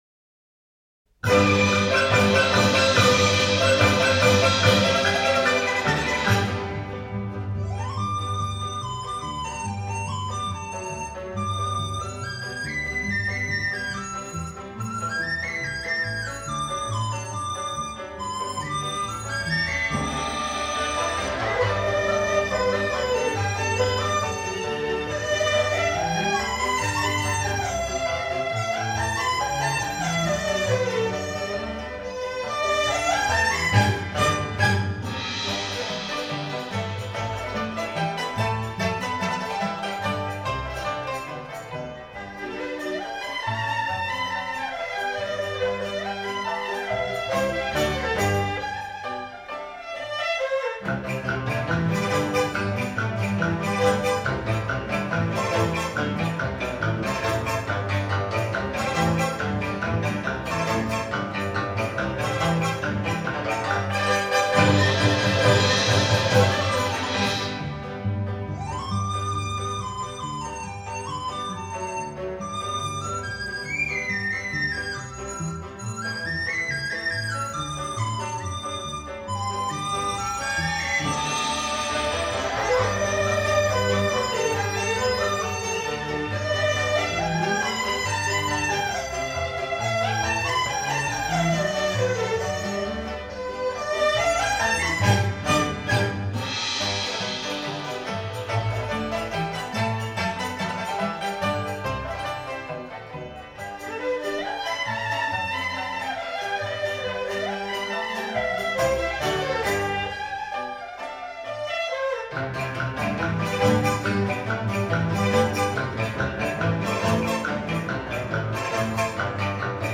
音乐类型: 民乐
中国人过年过节、家中有喜事的时候讲究欢天喜地，整张专辑的多数作品是根据中国民歌改编的，喜庆、欢快、热闹，节日气氛很浓。
而且由中国民族管弦乐队演奏，尤其是笛子、二胡等的鲜明演奏，民族特色非常浓郁。